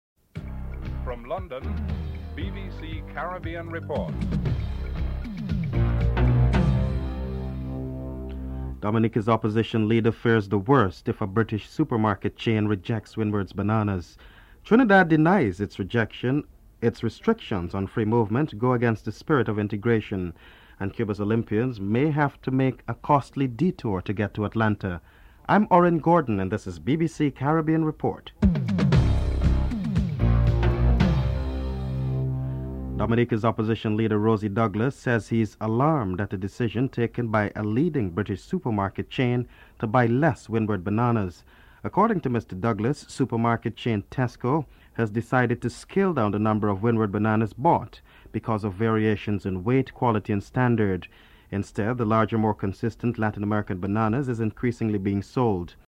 Black Power Advocate Stokely Carmichael is interviewed (04:00-08:49)